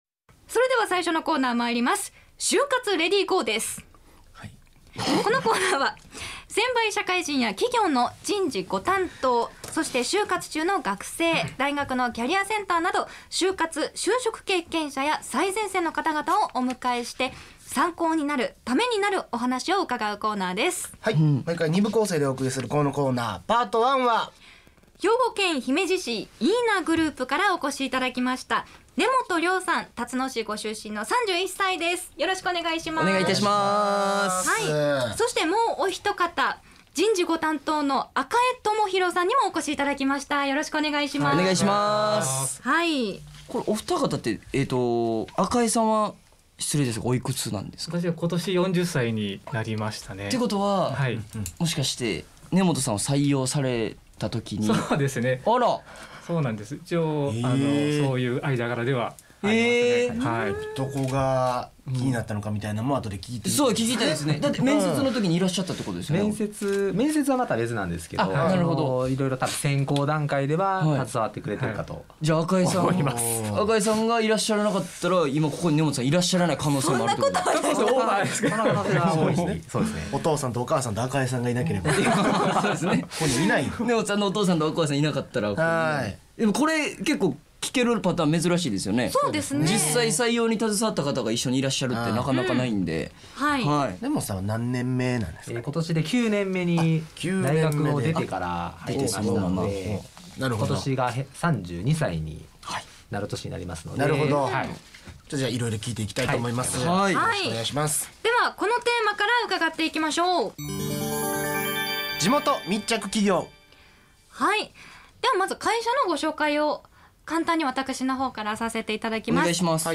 社会人として活躍する先輩たちは、いったいどんな就職活動を経験し、今日に至るのか。先輩社会人ロールモデルが、ラジオ番組でのその実体験を語った。